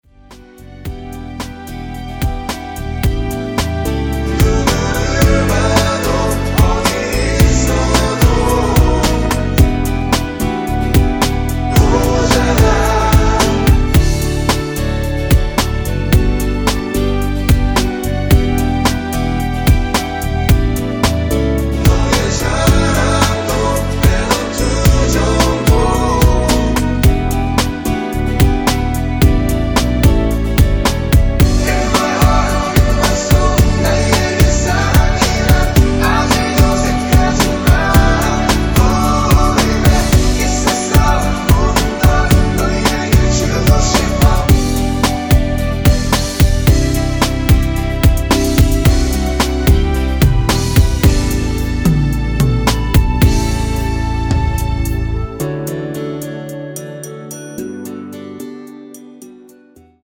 원키에서(-1)내린 멜로디와 코러스 포함된 MR입니다.(미리듣기 확인)
◈ 곡명 옆 (-1)은 반음 내림, (+1)은 반음 올림 입니다.
앞부분30초, 뒷부분30초씩 편집해서 올려 드리고 있습니다.